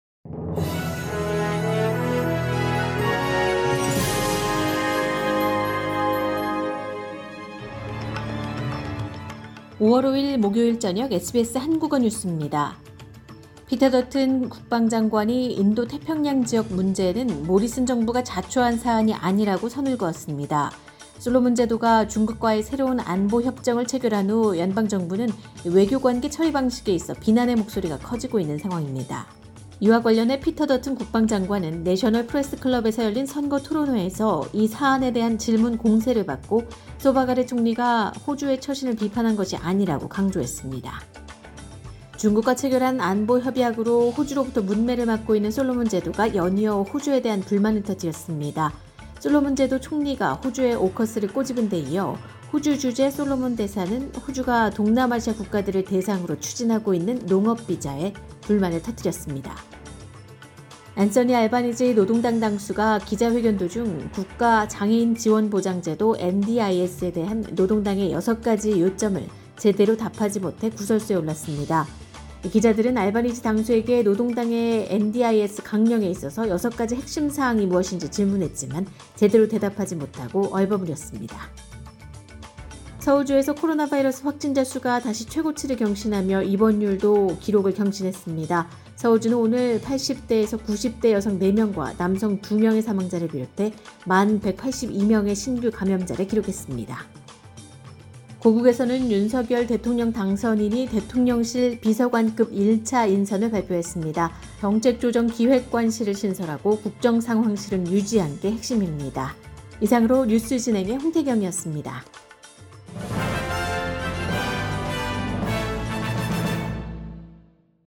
SBS 한국어 저녁 뉴스: 2022년 5월 5일 목요일
2022년 5월 5일 목요일 저녁 SBS 한국어 간추린 주요 뉴스입니다.